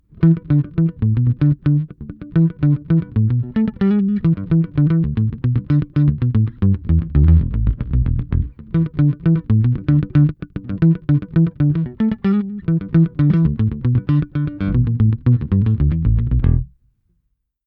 Bass VI
Elle est accordée comme une guitare mais à l’ octave inférieure.
Ici 2 extraits , un au médiator et l’ autre en arpège.